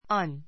an 小 A1 ən アン 冠詞 1つの , 1人の ✓ POINT anは発音が母音 ぼいん で始まる語の前につける. an の n と次の母音を続けて発音する.